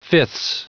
Prononciation du mot fifths en anglais (fichier audio)
Prononciation du mot : fifths
fifths.wav